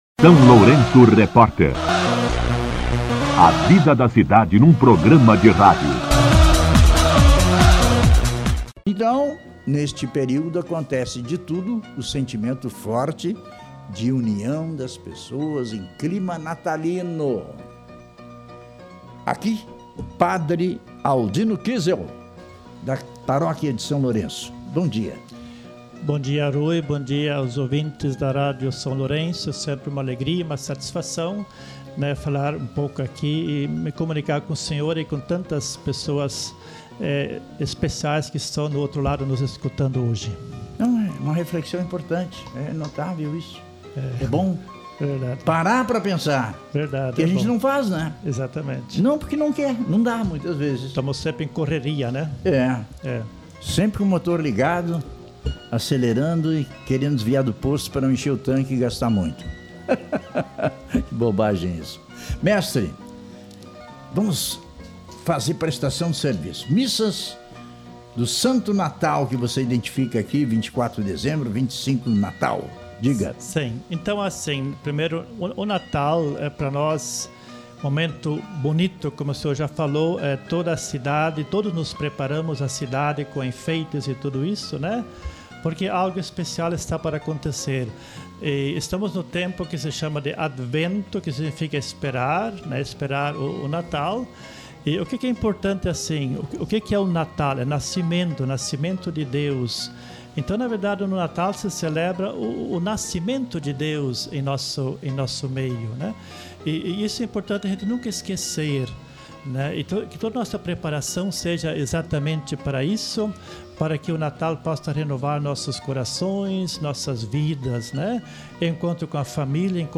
Entrevista-padre-19.mp3